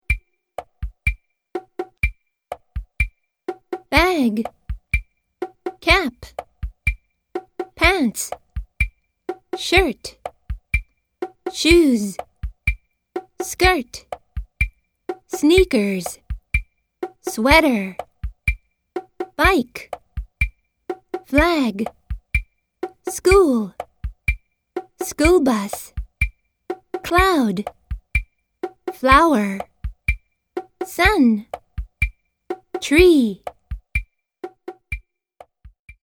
１　単語読みがリズム読みに。
Words欄の語彙：2ndは「読み」、3rdは「リズム読み」